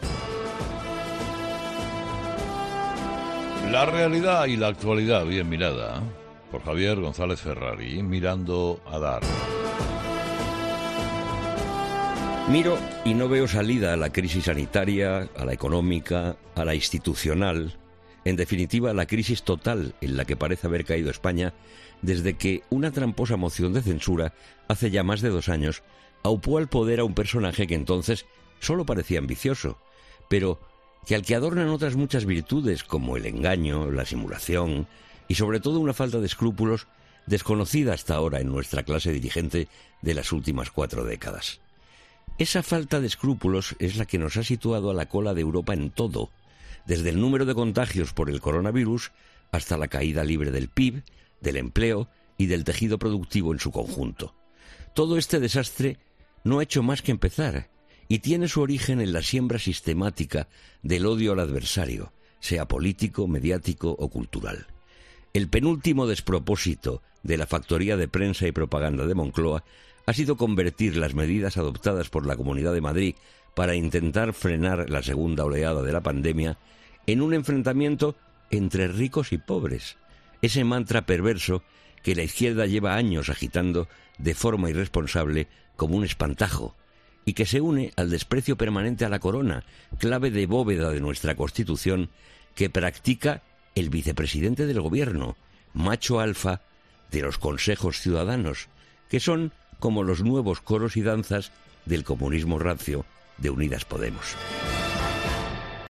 El tertuliano de "Herrera en COPE" analiza las reacciones a la decisión de la Comunidad de Madrid de confinar algunas zonas de la región para controlar la pandemia